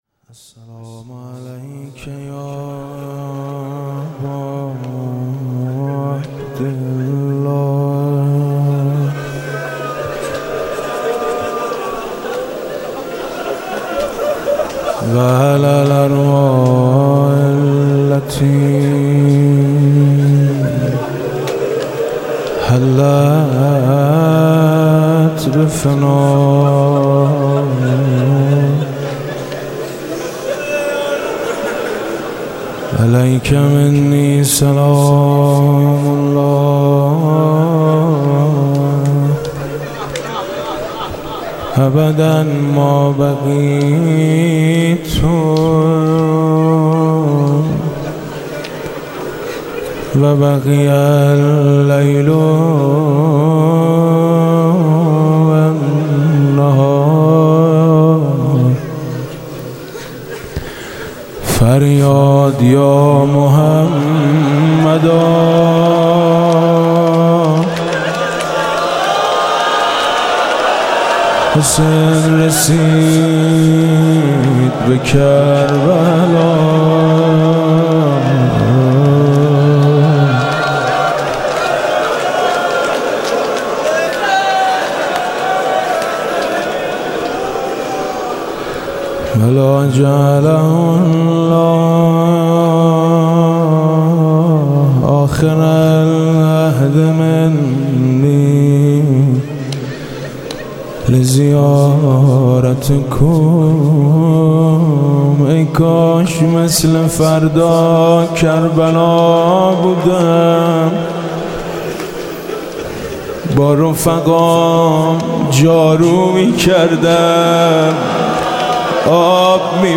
مراسم عزاداری و سوگواری سرور و سالار شهیدان حضرت اباعبدالله الحسین(ع) در محرم ۱۴۳۷ هـ.ق با سخنرانی حجت الاسلام والمسلمین پناهیان و مداحی حاج میثم مطیعی به مدت ۱۱ شب از ۲۲ مهر بعد از نماز مغرب و عشاء در هیئت میثاق با شهدا واقع در بزرگراه چمران، پل مدیریت، دانشگاه امام صادق(ع) برگزار می‌شود.
صوت مراسم شب دوم محرم ۱۴۳۷ هیئت میثاق با شهدا ذیلاً می‌آید: